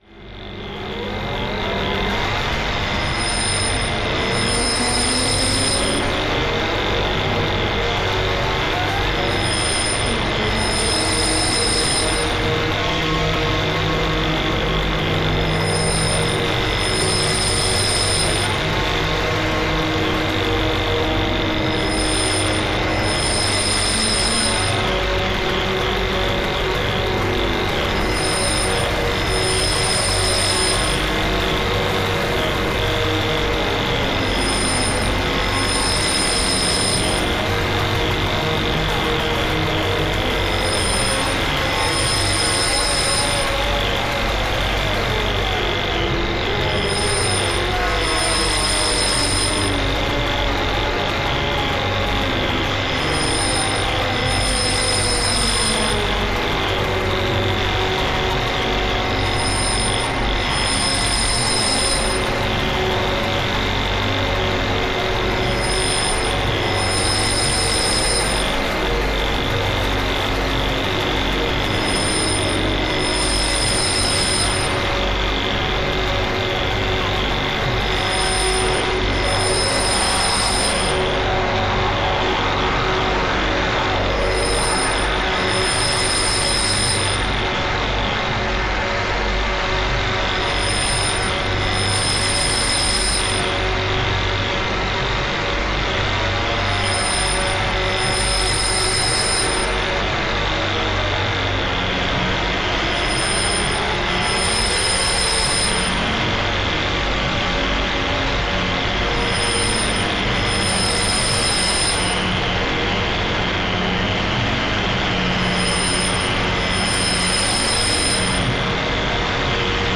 guitar and syntheziser